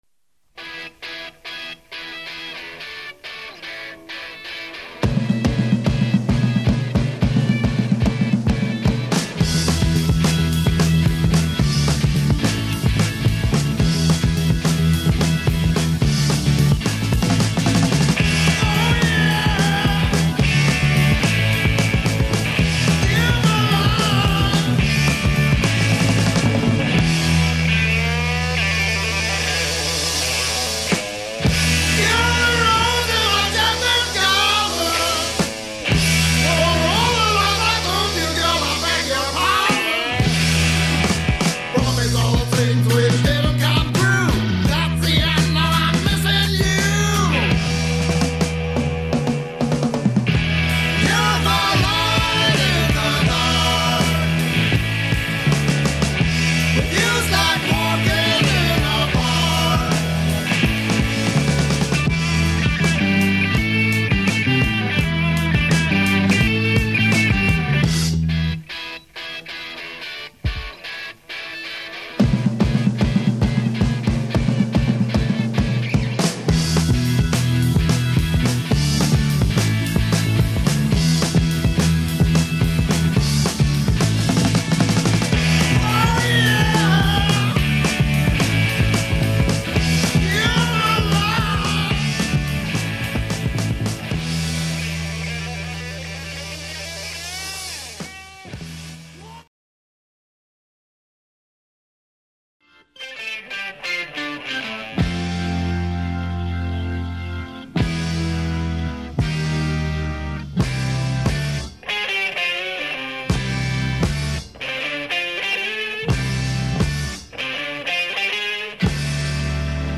Progressive / Symphonic